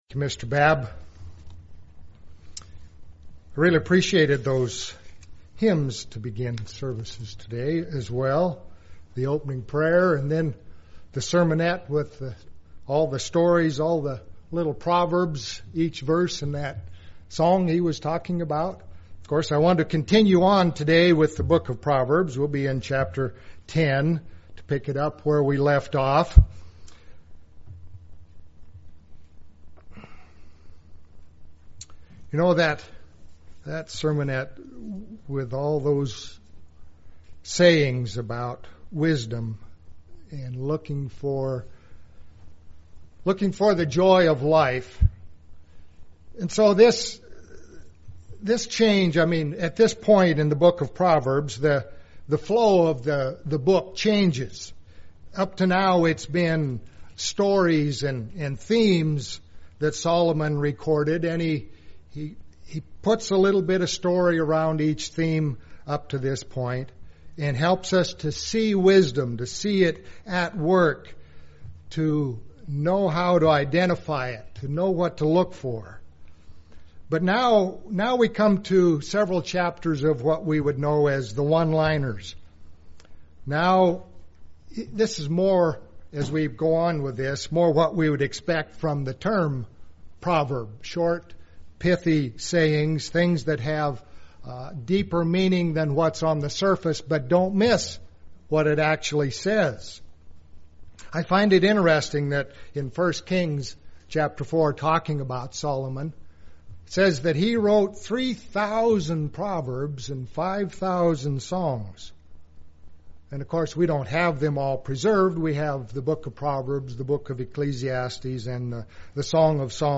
Sermon
Given in Medford, OR